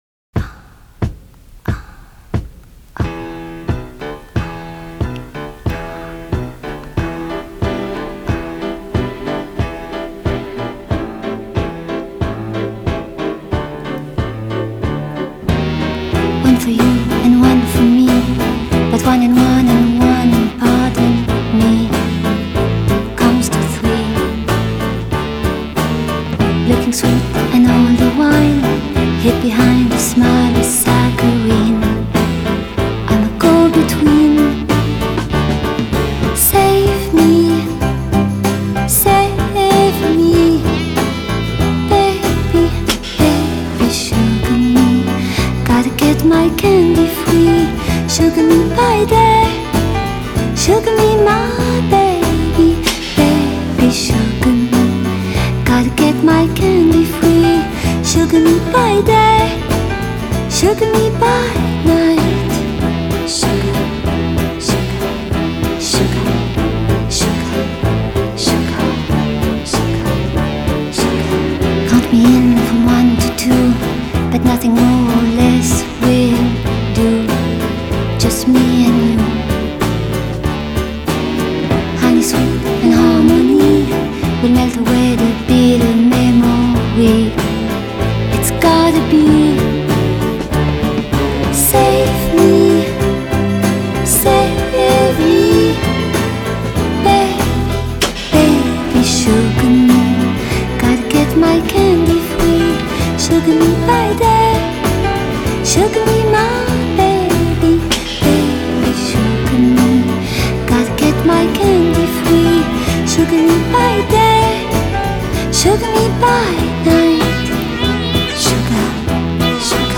То же хороший вариант- только голос у певицы ниочём ( т.е. никакой) (имхо).